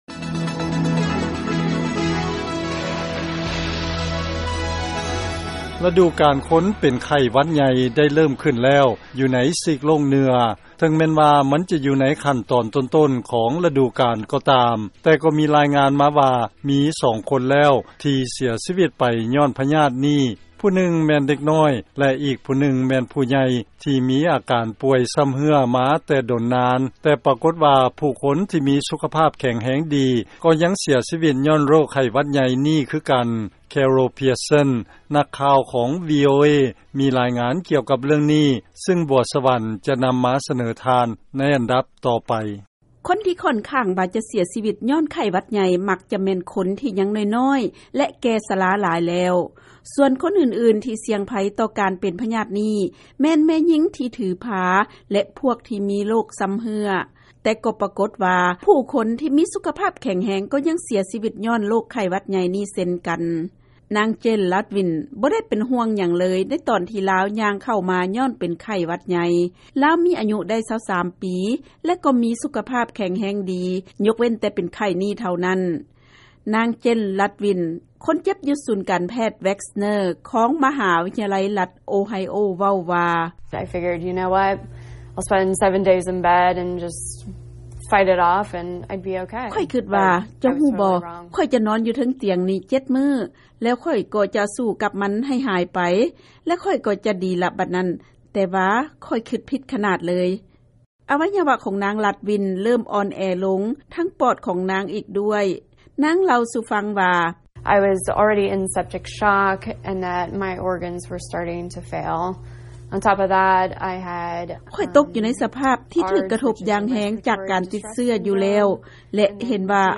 ເຊີນຟັງລາຍງານກ່ຽວກັບອັນຕະລາຍຂອງໄຂ້ຫວັດໃຫຍ່